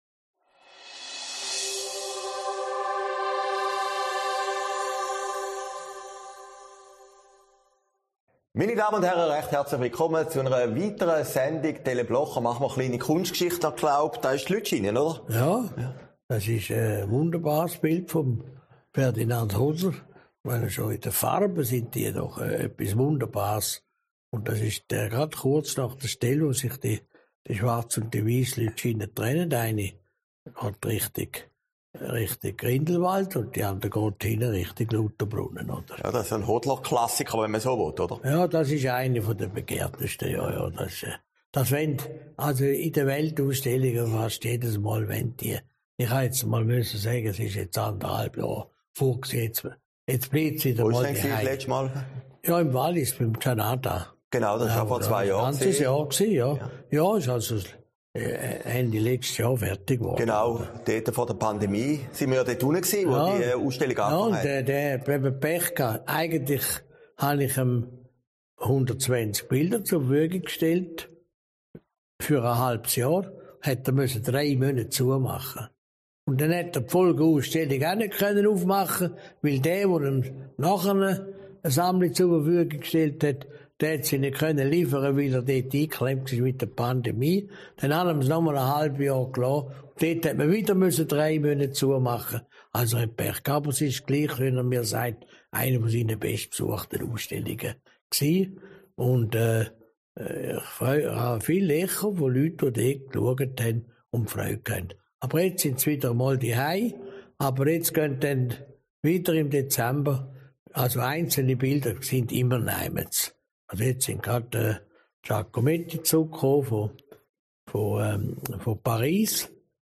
Sendung vom 26. November, aufgezeichnet in Herrliberg